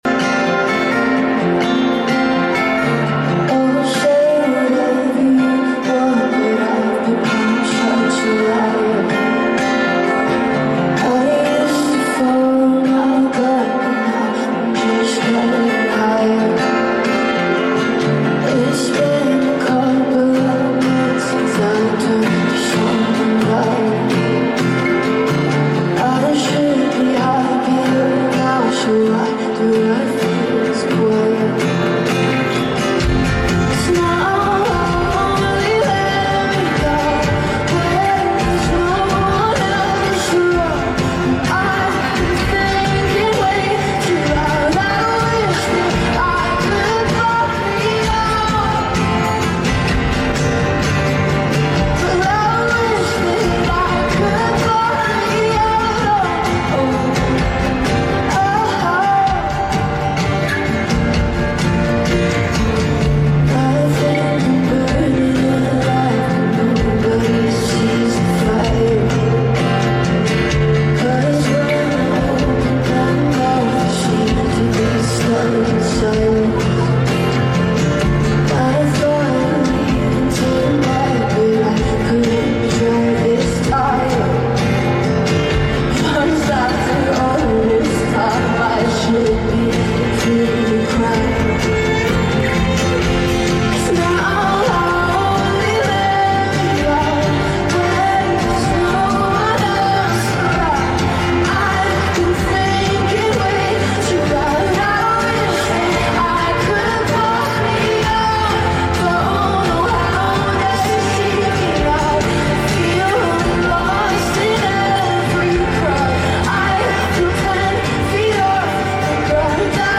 now at a stadium